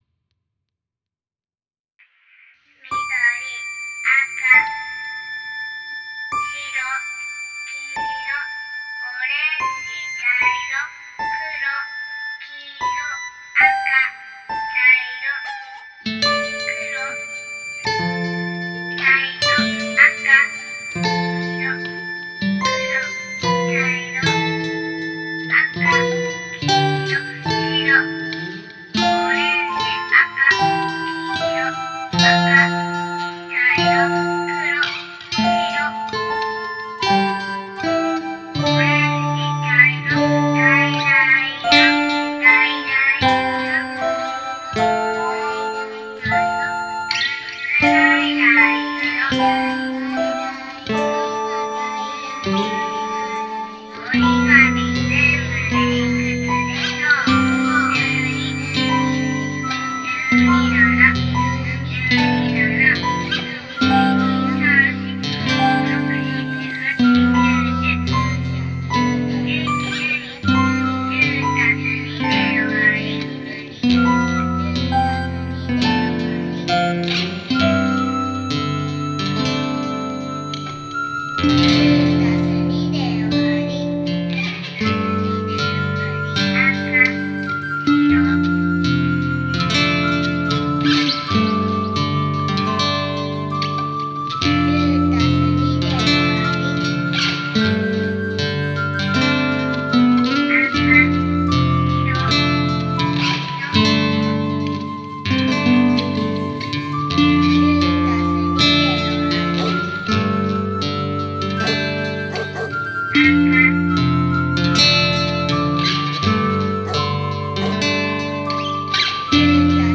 ギター、うた、笛、ピアノ、打楽器